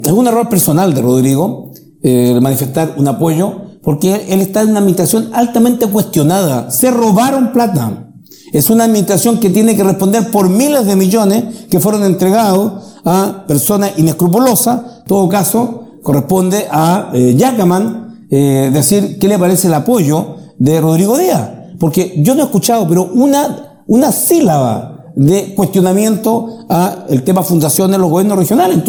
Fue en el debate organizado por Canal 9, al que Giacaman no asistió, en que Navarro insistió en que el apoyo brindado por Diaz fue un error y que todo obedece a un posible acuerdo en las sombras entre ambos.